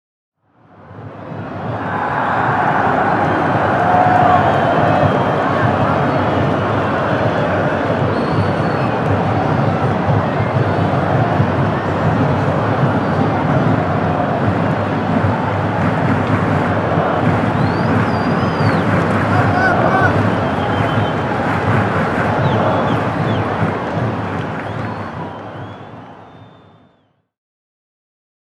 voetbalpubliek_ (1)
voetbalpubliek_-1.mp3